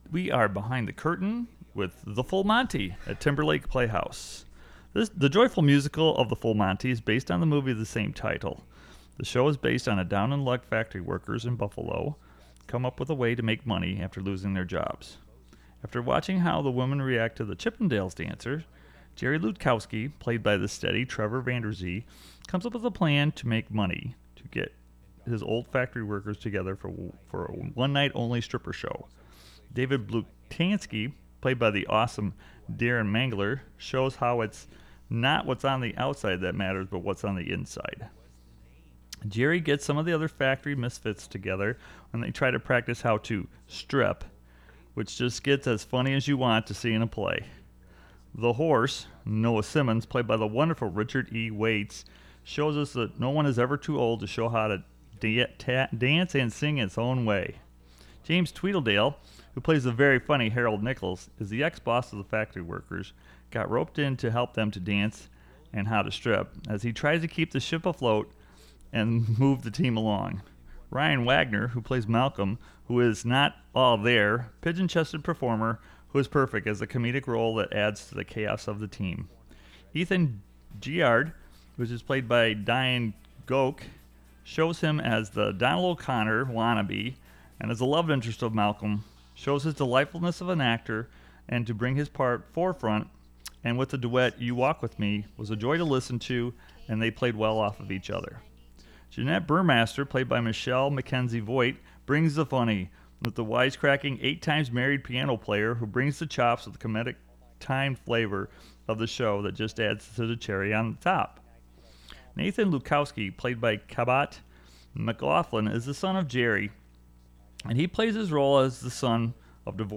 TLP-FullMonty-REVIEW.wav